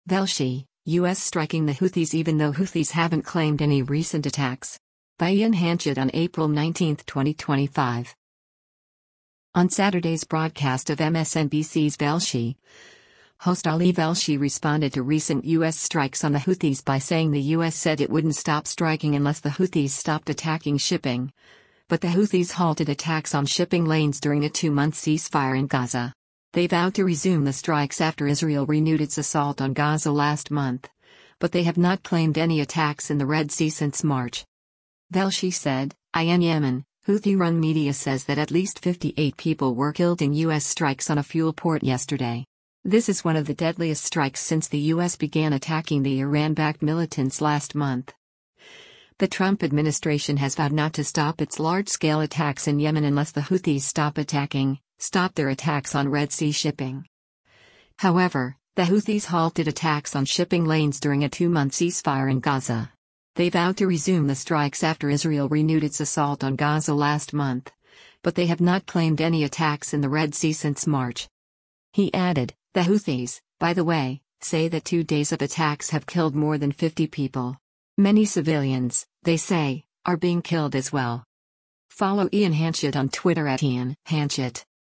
On Saturday’s broadcast of MSNBC’s “Velshi,” host Ali Velshi responded to recent U.S. strikes on the Houthis by saying the U.S. said it wouldn’t stop striking unless the Houthis stopped attacking shipping, but “the Houthis halted attacks on shipping lanes during a two-month ceasefire in Gaza. They vowed to resume the strikes after Israel renewed its assault on Gaza last month, but they have not claimed any attacks in the Red Sea since March.”